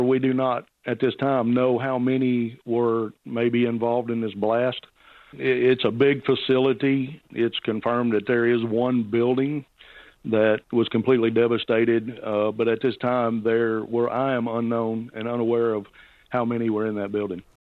The Mayor of Hickman County, Jim Bates, says it could be some time before the exact number of casualties is confirmed: